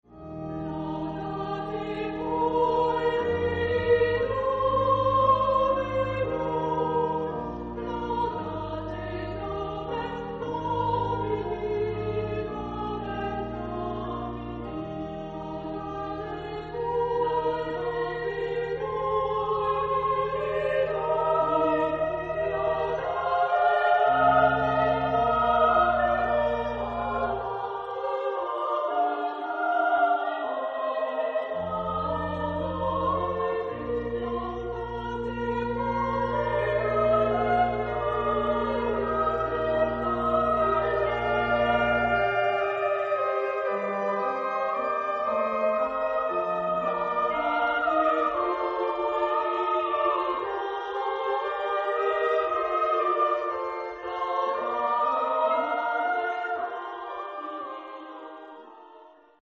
Género/Estilo/Forma: Sagrado ; Romántico ; Motete ; Salmo
Carácter de la pieza : allegro moderato ; solemne
Tipo de formación coral: SSA  (3 voces Coro femenino )
Solistas : Soprano (2) / Alto (1)  (3 solista(s) )
Instrumentación: Organo O Teclado  (1 partes instrumentales)
Instrumentos: órgano (1) o teclado (1)
Tonalidad : la bemol mayor ; mi bemol mayor